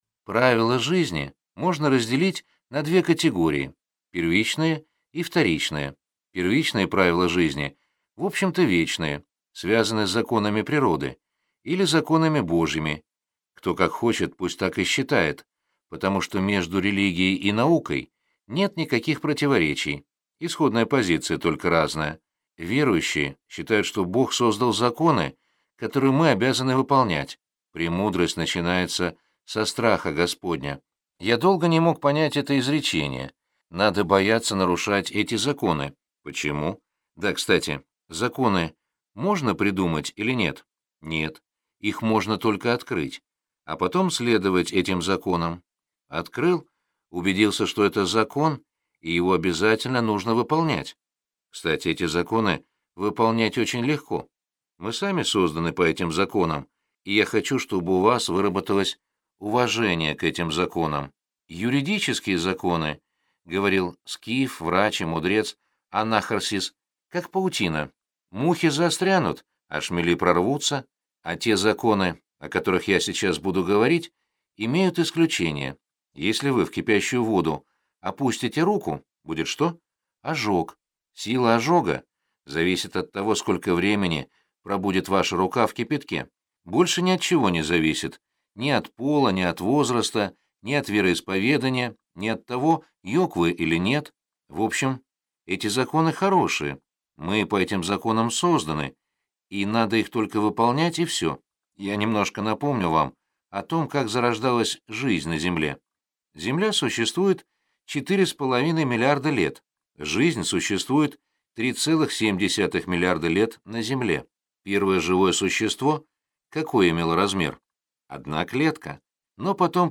Аудиокнига 7 шагов к успеху | Библиотека аудиокниг